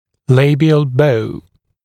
[‘leɪbɪəl bəu][‘лэйбиэл боу]вестибулярная дуга